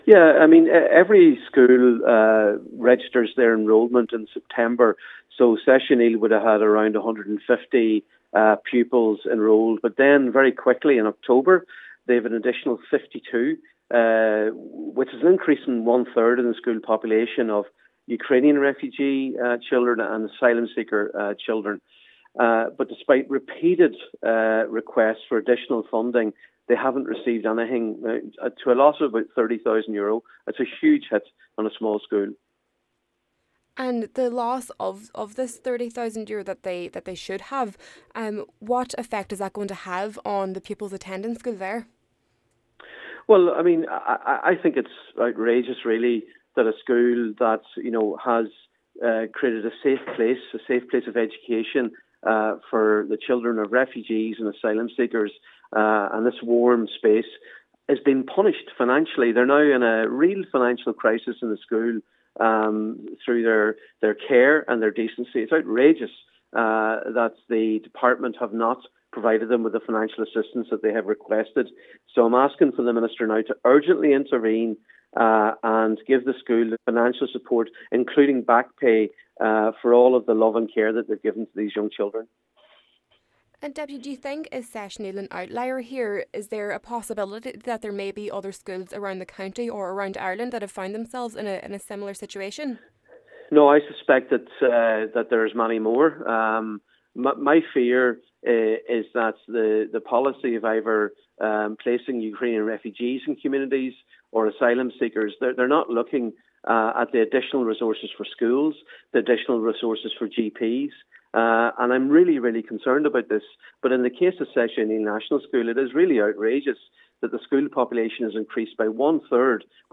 Deputy Padraig Mac Lochlainn says it’s outrageous that a school who provided a safe environment for children is being punished financially: